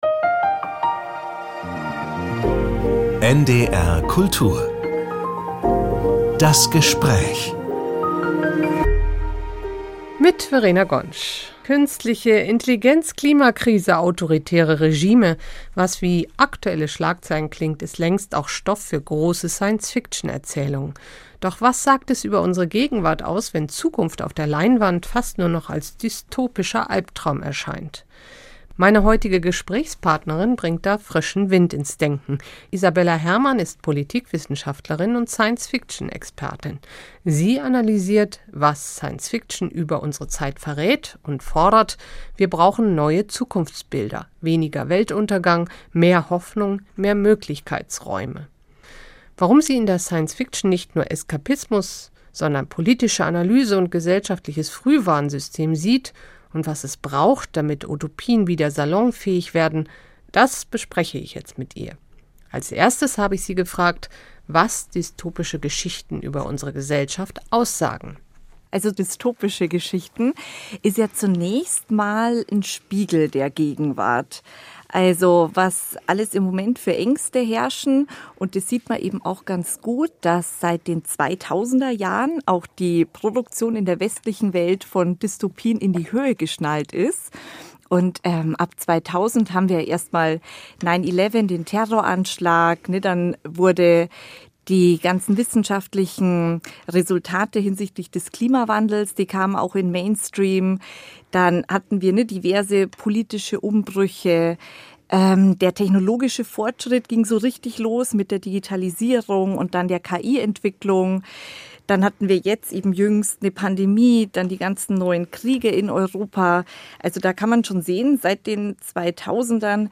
Warum Anti-Dystopien Zukunft haben ~ NDR Kultur - Das Gespräch Podcast